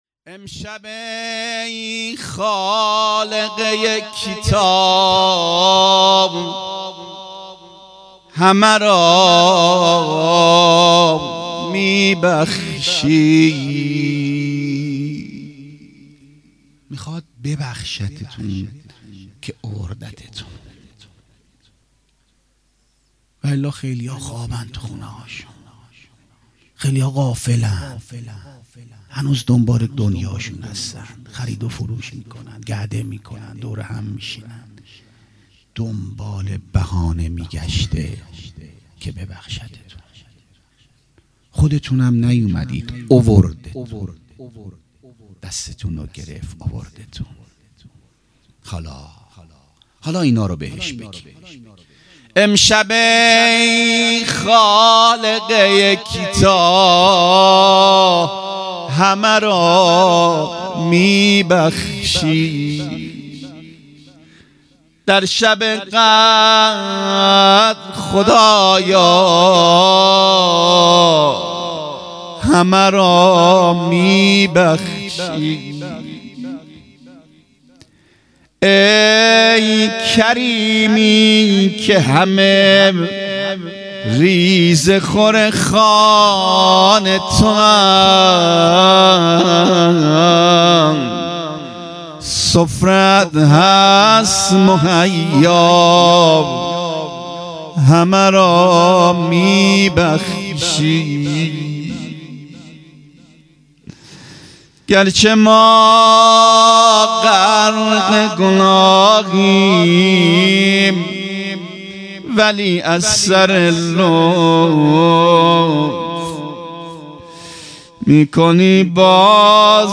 هیئت مکتب الزهرا(س)دارالعباده یزد
روضه|امشب ای خالق یکتا|بانوای گرم
شبهای قدر